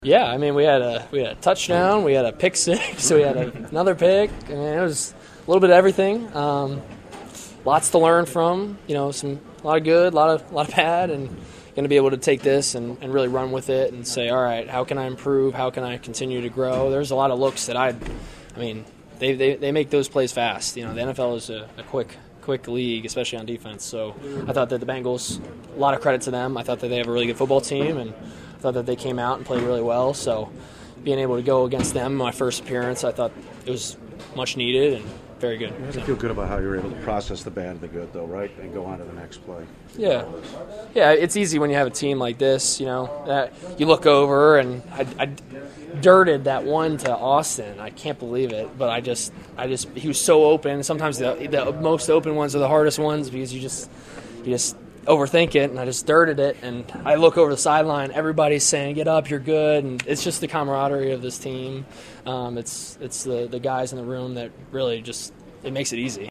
Caught up with Clifford in the locker room and asked him if he experienced just about every result possible in his professional debut: